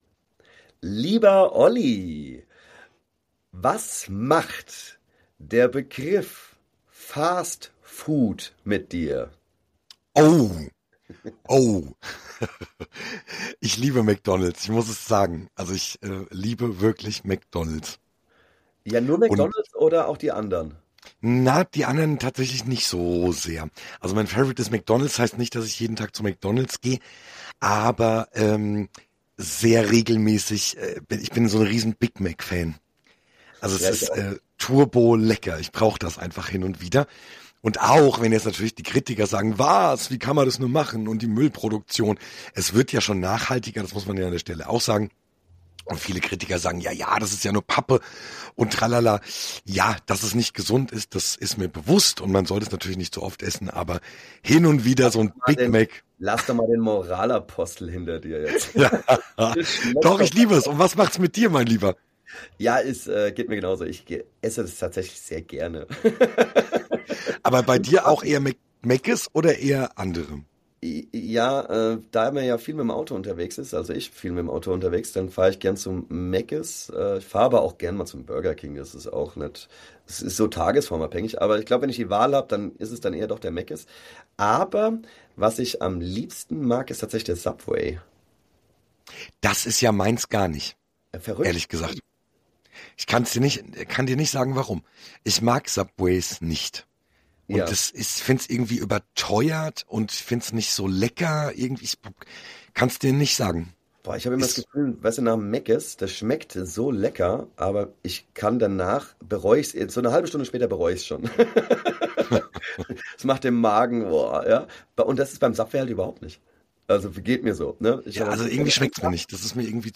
Wie immer kommen wir ohne Skript aus und behandeln entspannte, aber auch ernste Themen die uns zur Zeit beschäftigen.